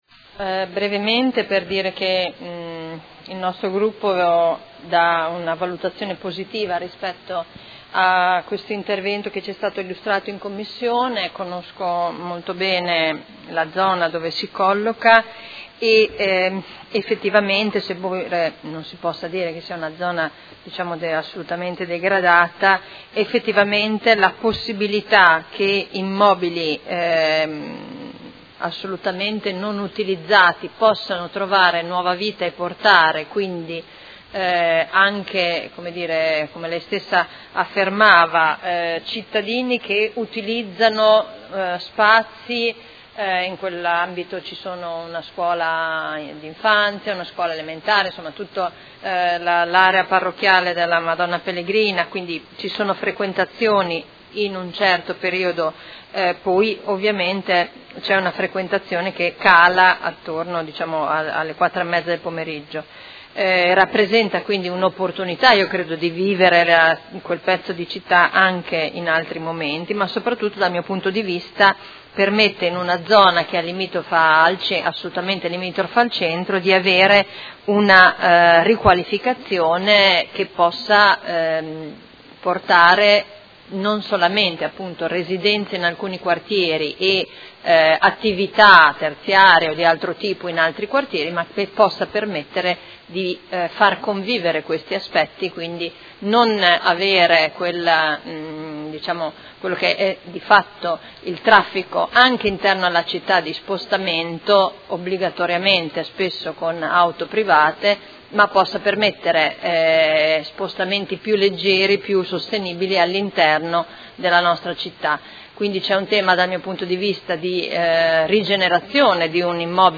Seduta dell’8/11/2018.